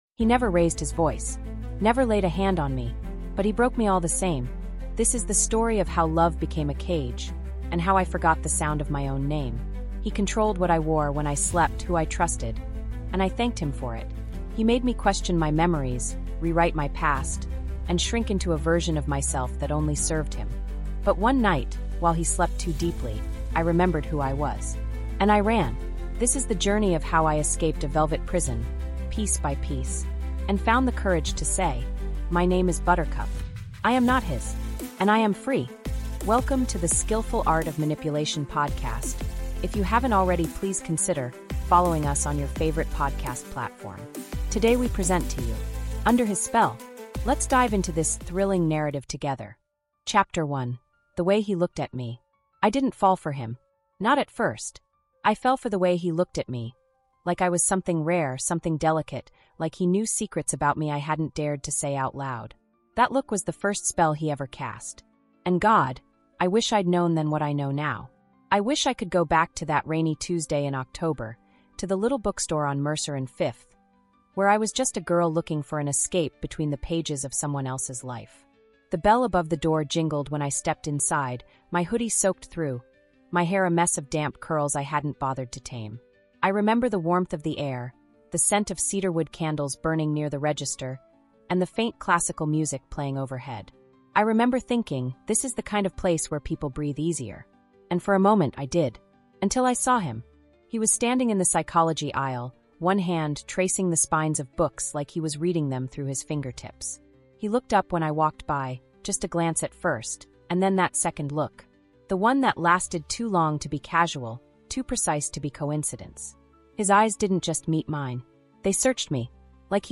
Under His Spell is a gripping, emotionally raw, 7-chapter psychological drama told from the first-person perspective of Buttercup—a woman slowly unraveling under the influence of a charming, manipulative partner. What starts as affection spirals into obsession.